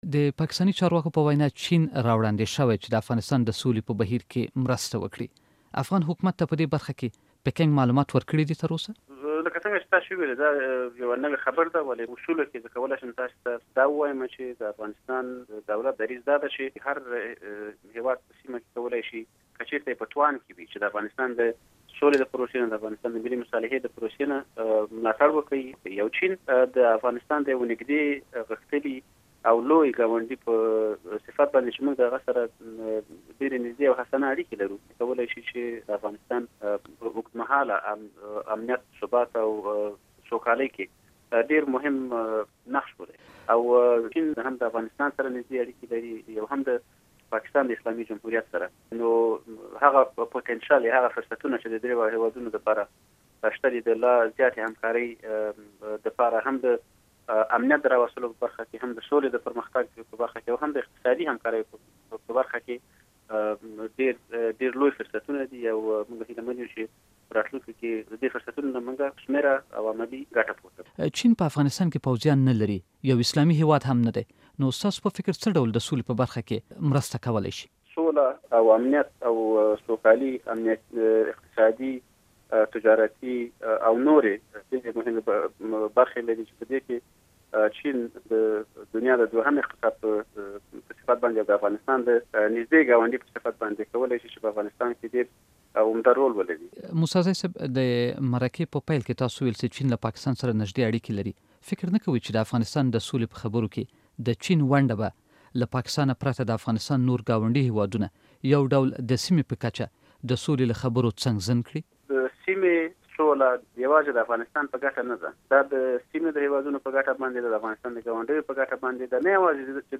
له جانان موسى زي سره مرکه